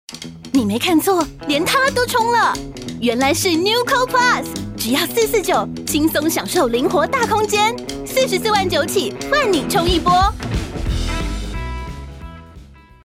國語配音 女性配音員
她擅長國語配音，聲線既能俏皮活潑，也能沉靜溫柔，特別擅長詮釋少年少女角色與富有童趣的動畫情節。
• 聲線清新、富有生命力，擁有強烈親和力，適合各類動畫、遊戲角色